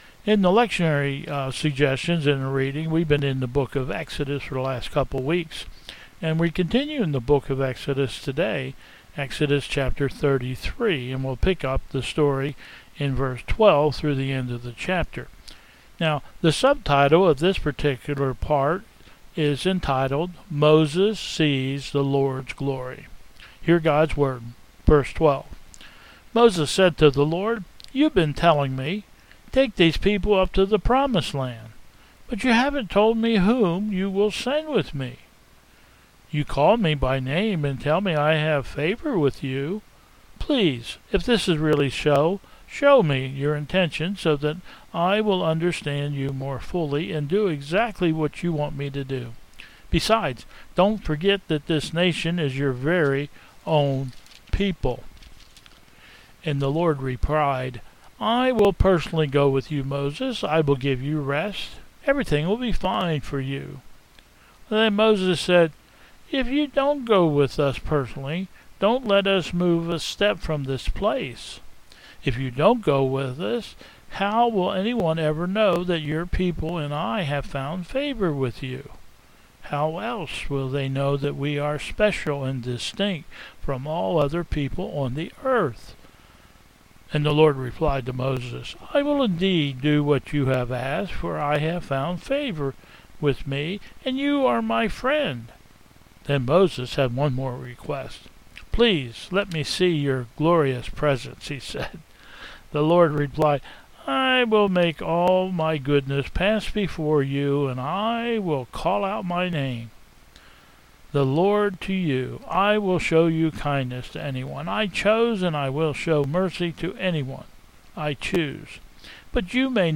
Bethel 10/18/20 Service
Processional